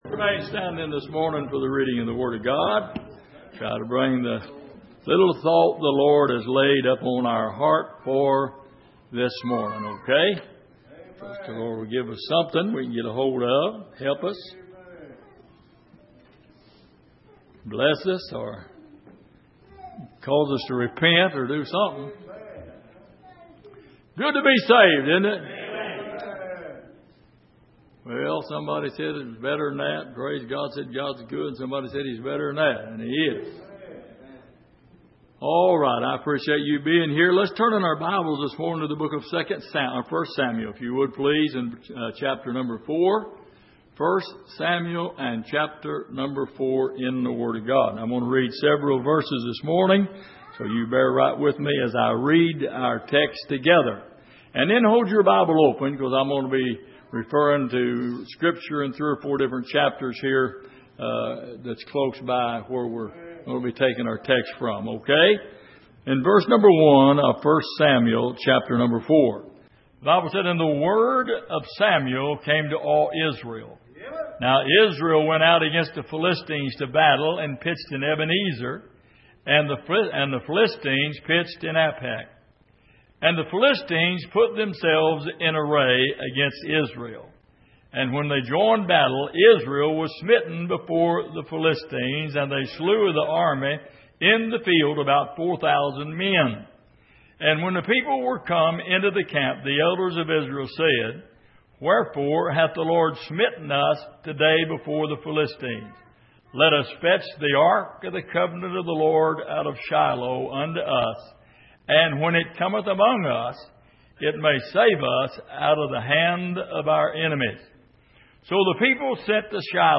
Passage: 1 Samuel 4:1-22 Service: Sunday Morning